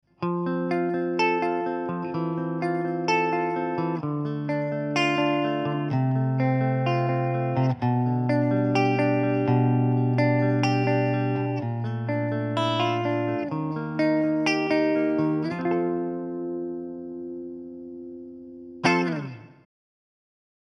Alle Soundbeispiele wurden mit einer Harley Benton Fusion T eingespielt und mit einem Shure SM57 abgenommen. Dazu kommt ein wenig Hall von einem Keeley Caverns Pedal.
1 Watt, Volume 5, Tone 2 Coil Split Middle Position, Clean Chords